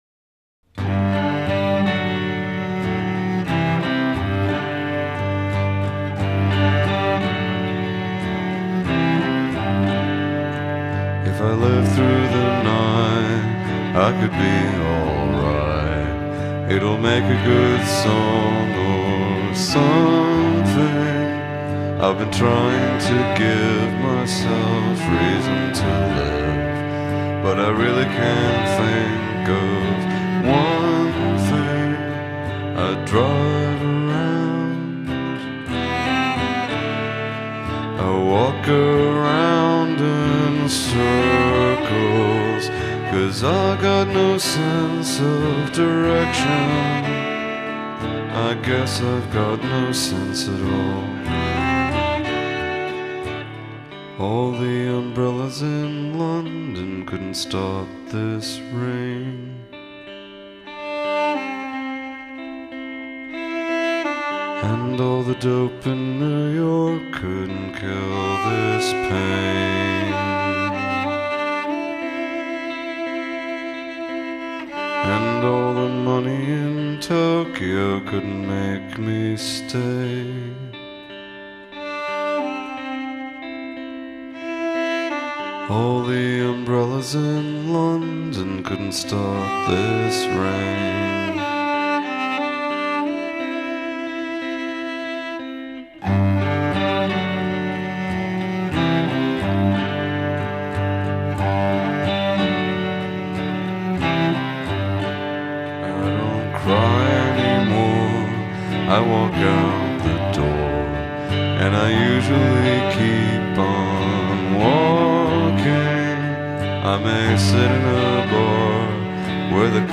the cello strokes underlining the chorus